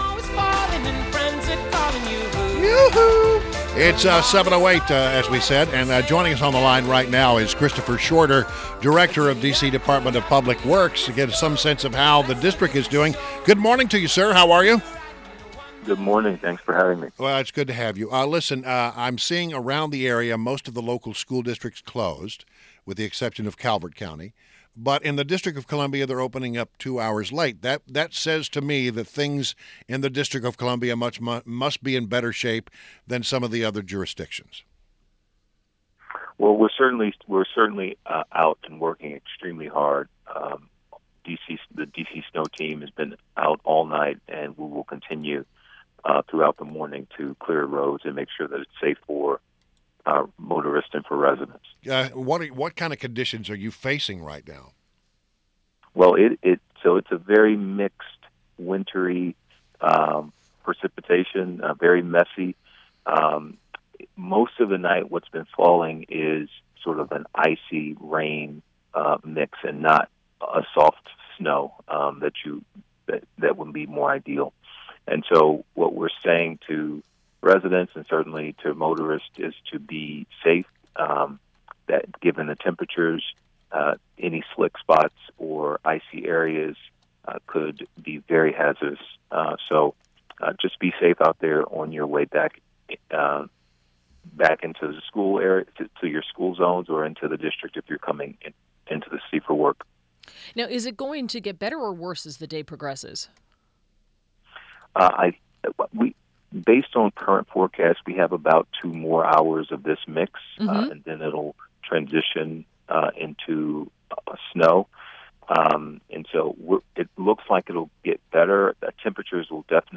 Interview – CHRISTOPHER SHORTER – DC Director of the Department of Public Works — recapped how DC prepared in anticipation of the snow.